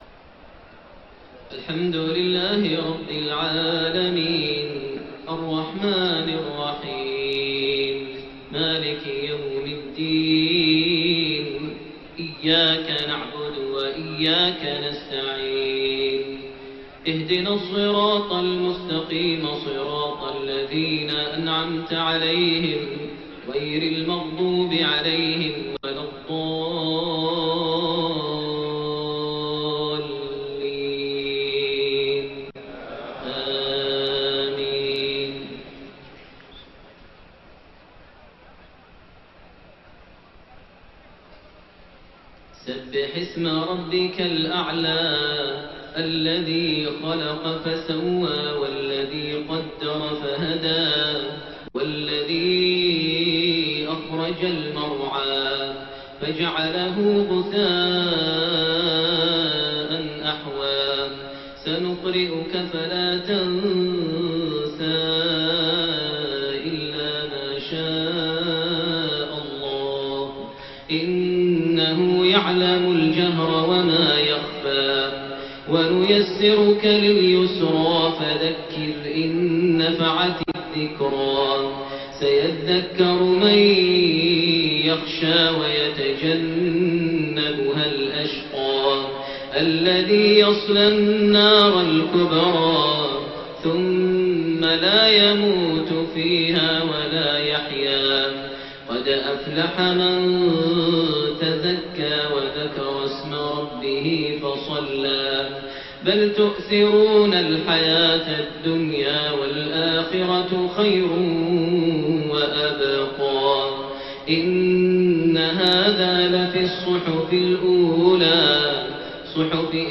Isha prayer from Surat Al-A'laa and Ad-Dhuhaa > 1430 H > Prayers - Maher Almuaiqly Recitations